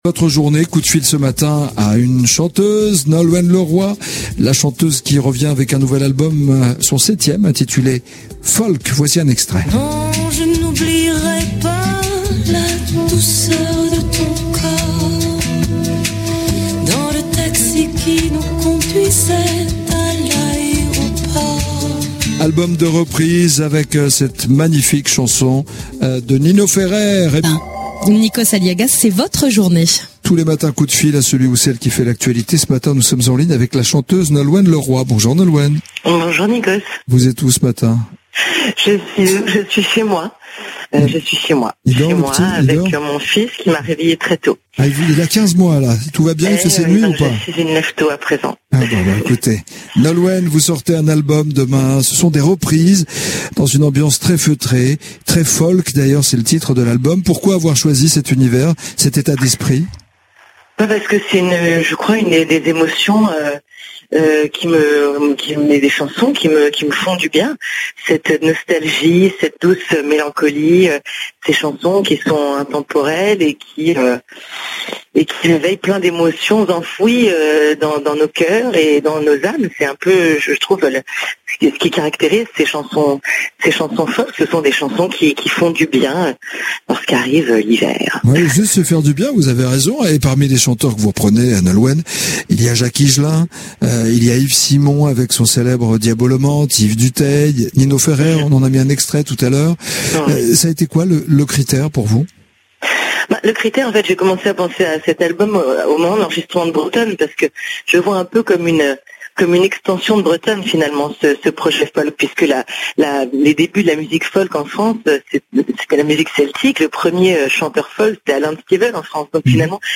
Sujet : Nikos..europe 1..coup de fil a Nolwenn (mp3 p 1)